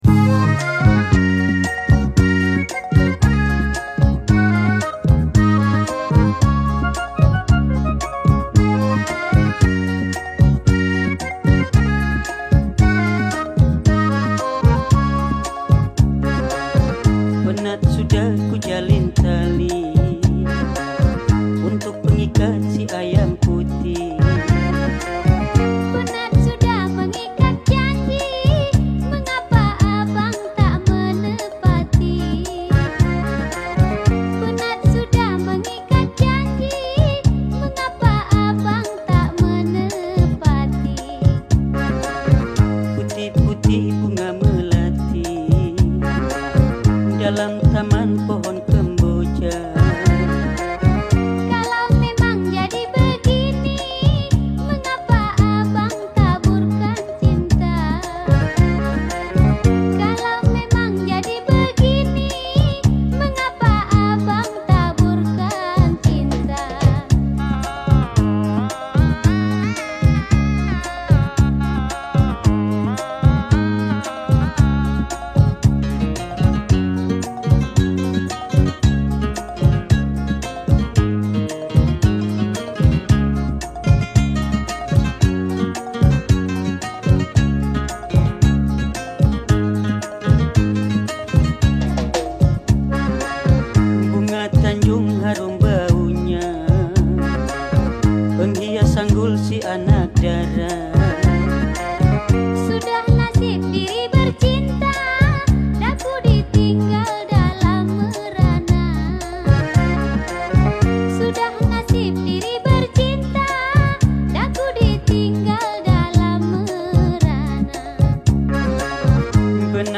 Siapa yang minat lagu joget melayu deli kita satu geng...